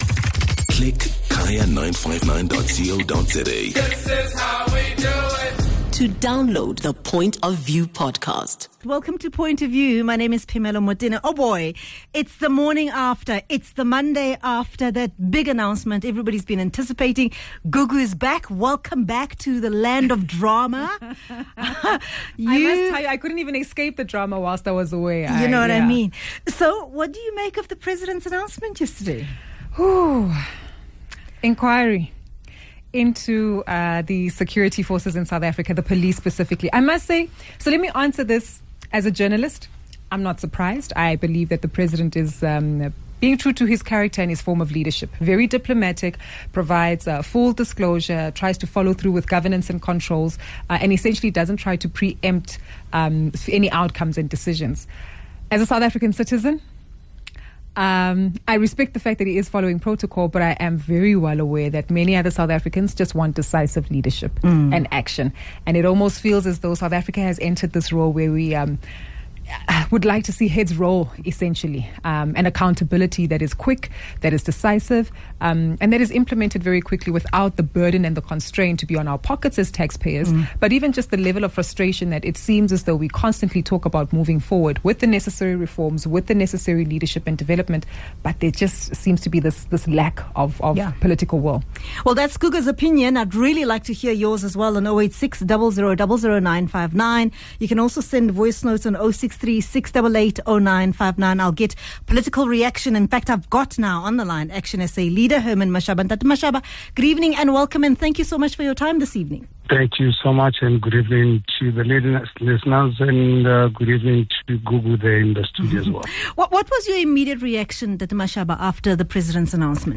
gets reaction from ActionSA Leader Herman Mashaba, EFF MP Mazwi Blose and the Chairperson of the Portfolio Committee on Police, Ian Cameron.